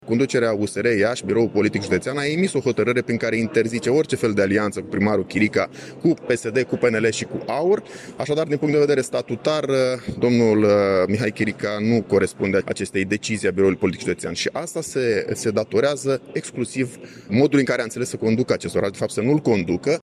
Pe de altă parte, senatorul USR Marius Bodea a spus că Biroul Politic Județean Iași al partidului a emis o hotărâre prin care este interzisă orice alianță cu primarul Mihai Chirica, dar și cu PNL, PSD și AUR.